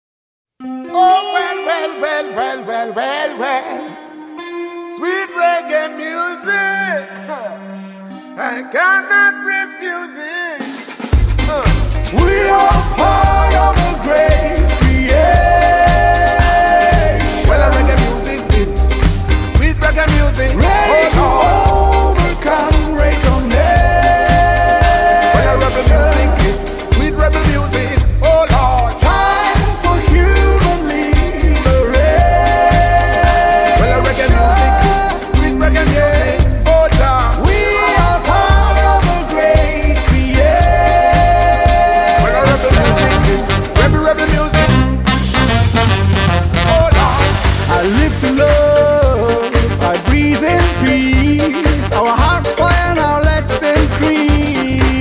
European Pop
with with a slight African taste